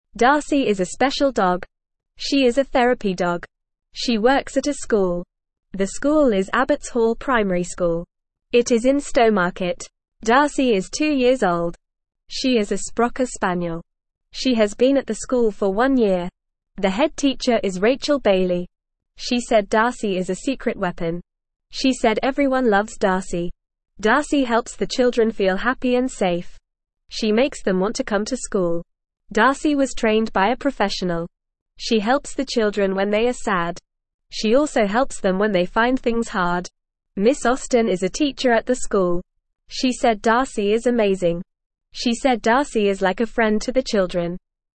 Fast
English-Newsroom-Beginner-FAST-Reading-Special-Therapy-Dog-Helps-School-Kids.mp3